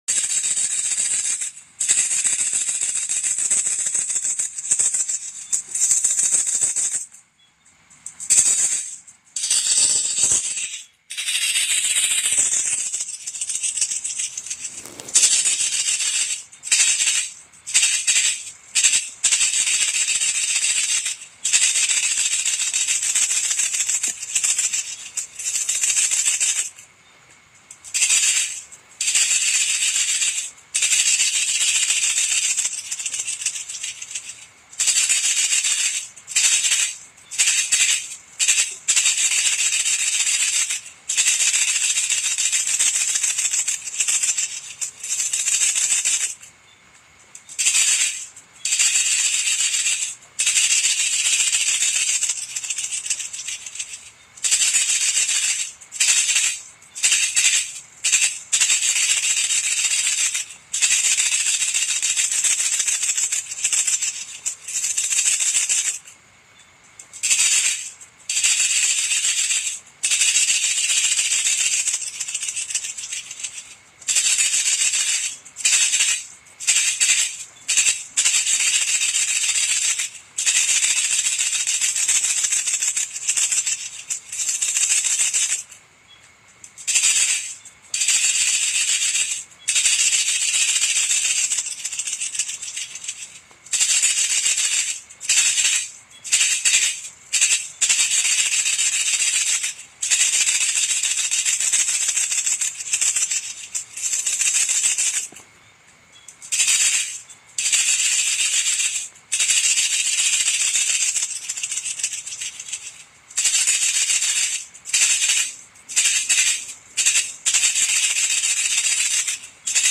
โหลด สียง ตอก ต่อหนู กลางคืน mp3 4sh ได้ฟรี และเสียงที่ได้จะชัดเจนและสมจริง Download เสียงสั่นตอกเรียกหนู/ตอกเรียกหนูพุก/ต่อนกพุก/หนูแผง ที่ชัดเจนและสมจริง
เสียง ตอก ต่อหนู กลางคืน
tieng-rung-goi-chuot-den-vao-ban-dem-th-www_tiengdong_com.mp3